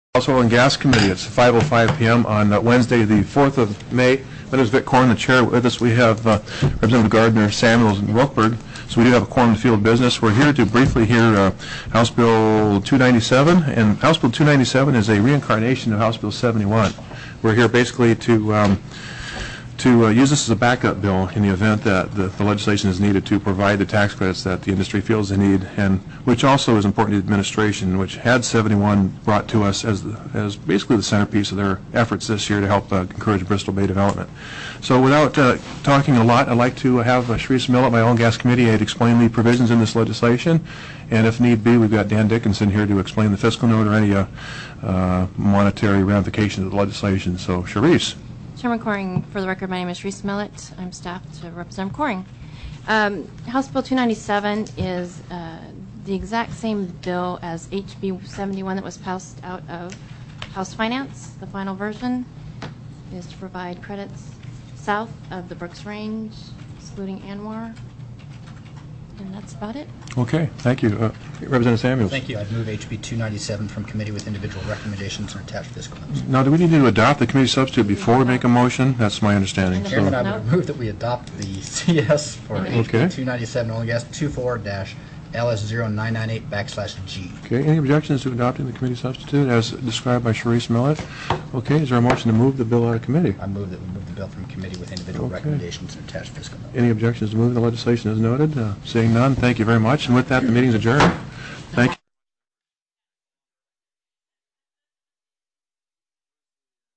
+ teleconferenced
*+ HB 297 OIL& GAS EXPLORATION CREDIT & LEASE TERMS TELECONFERENCED